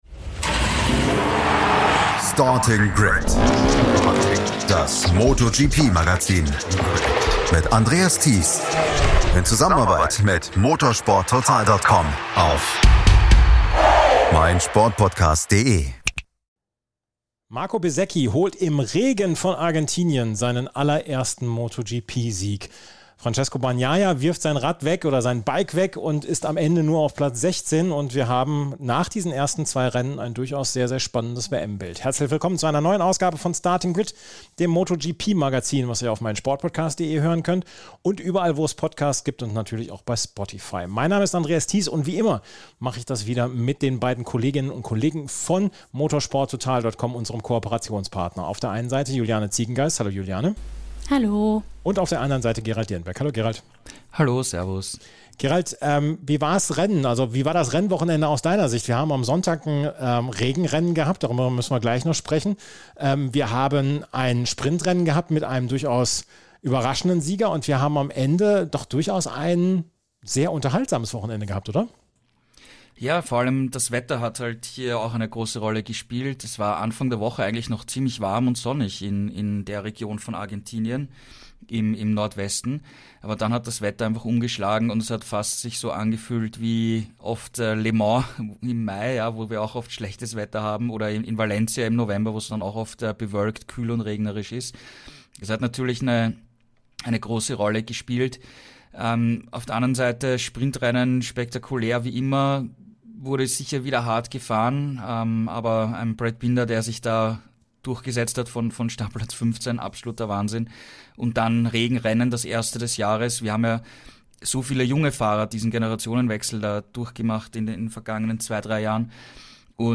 Zu dritt analysieren sie das Wochenende.